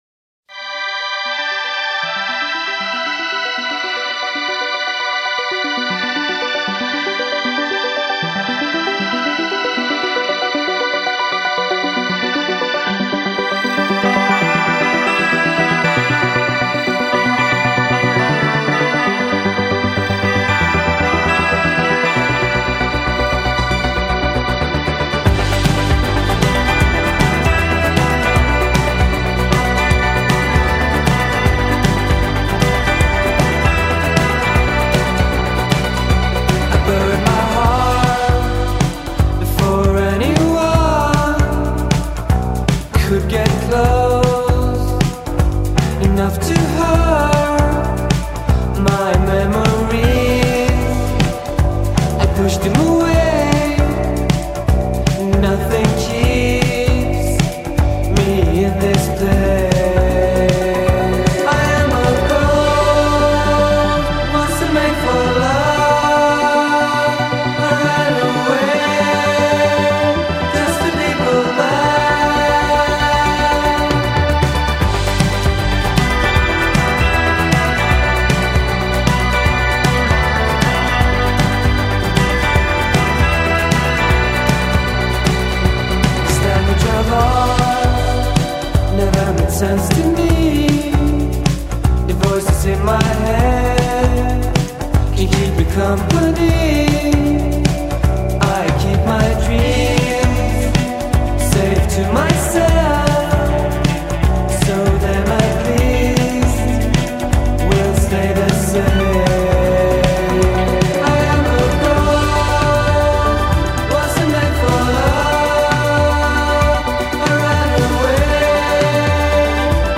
indie pop band